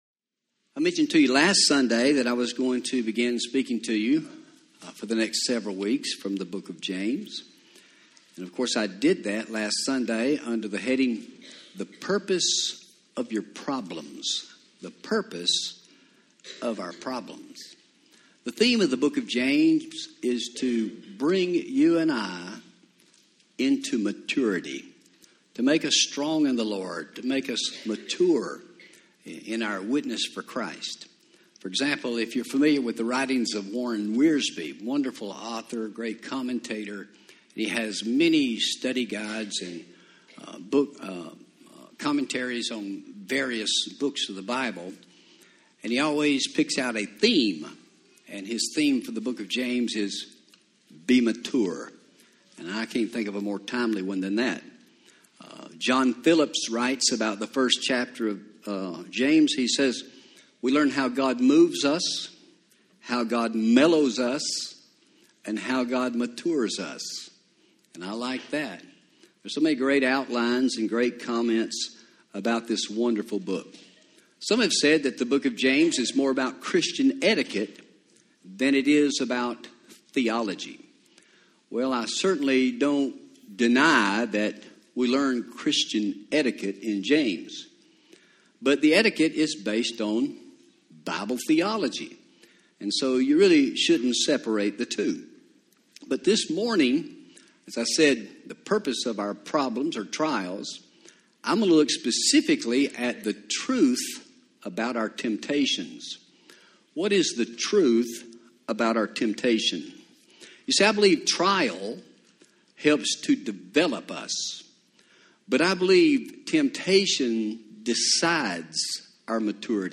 Home › Sermons › The Truth About Temptation